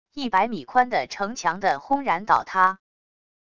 一百米宽的城墙的轰然倒塌wav音频